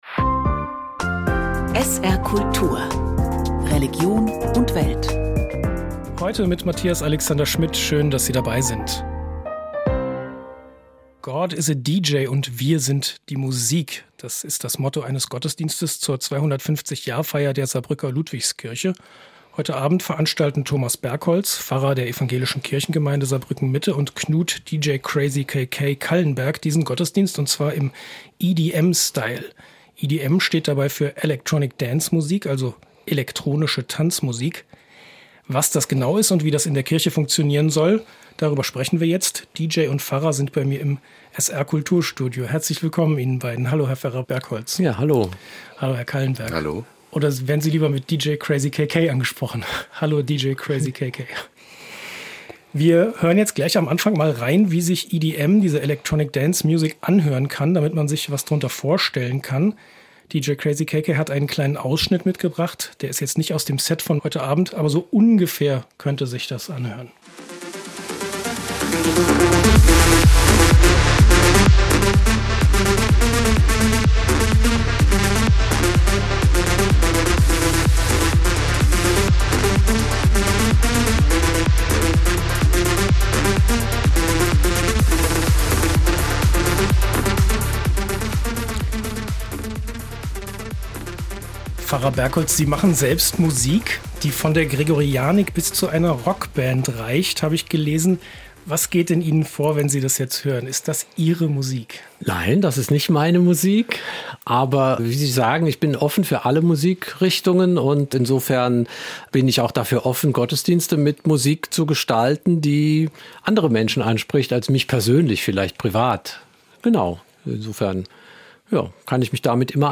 "Religion und Welt" ist eine von der Religionsredaktion des SR gestaltete Magazinsendung mit regionalen und überregionalen Themen an der Schnittstelle von Glaube, Kirche und Gesellschaft.